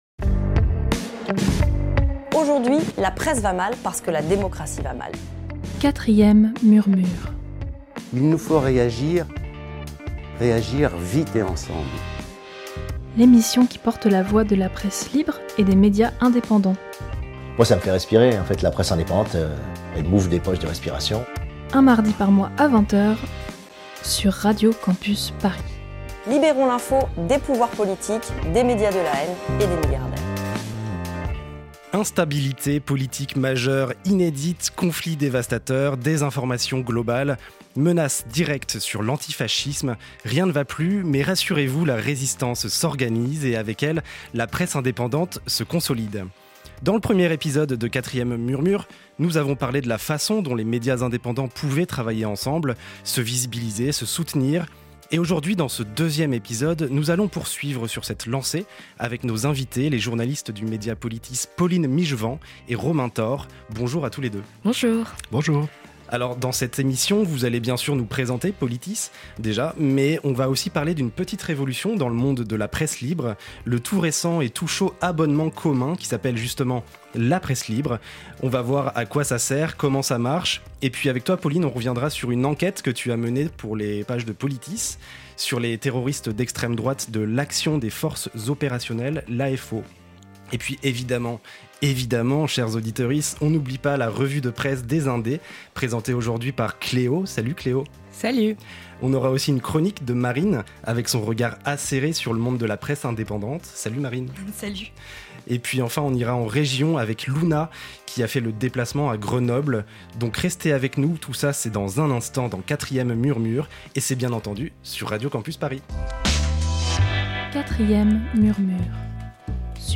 Dans ce deuxième épisode de 4e Murmure, nous recevons en plateau le média en ligne Politis.